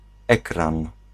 Ääntäminen
Synonyymit moniteur Ääntäminen France: IPA: [e.kʁɑ̃] Haettu sana löytyi näillä lähdekielillä: ranska Käännös Ääninäyte 1. monitor {m} 2. ekran {m} 3. zasłona {f} Suku: m .